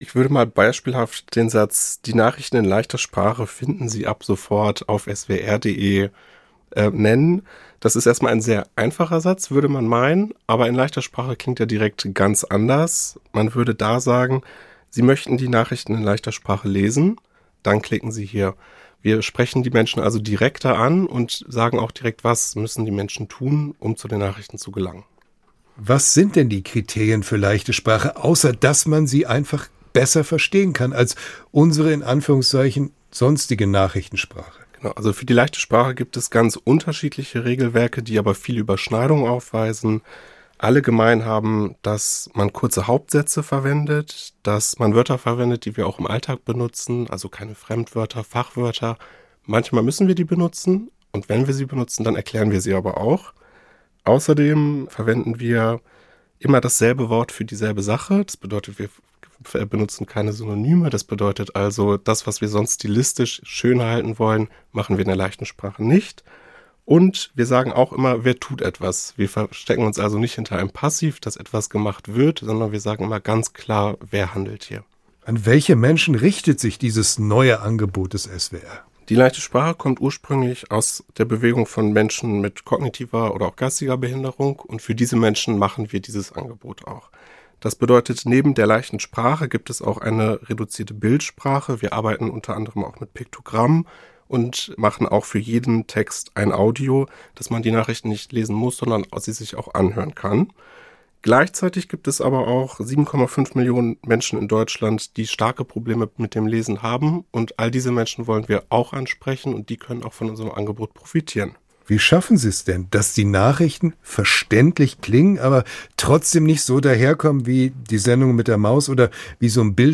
Interview zum Start der Nachrichten in Leichter Sprache